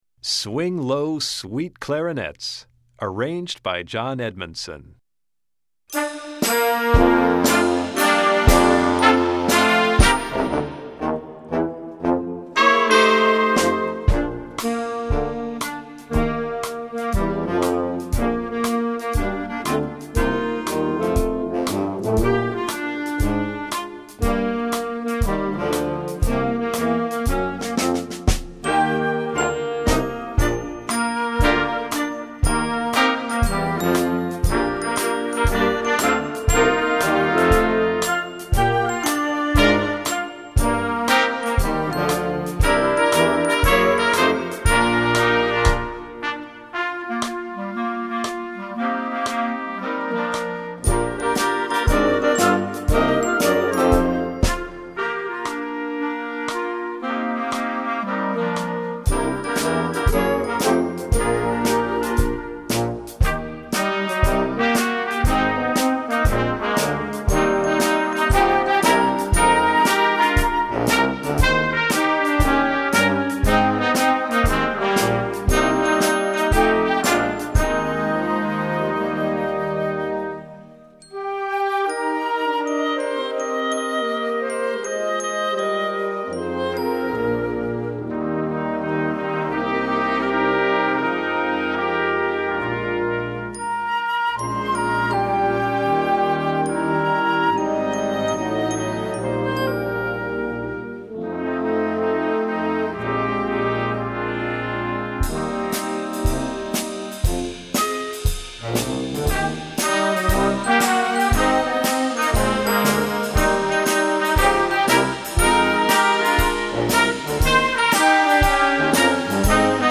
Voicing: Clarinet Section w/ Band